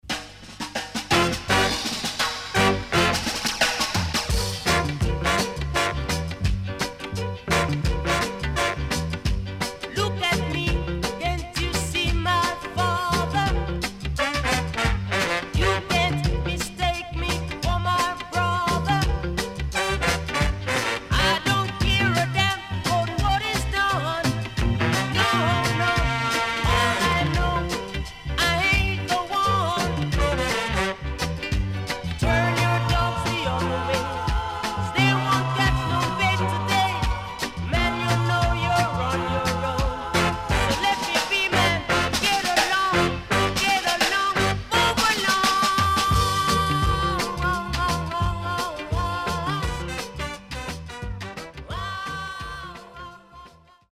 HOME > REGGAE / ROOTS
SIDE A:少しチリノイズ入りますが良好です。